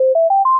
level_up.wav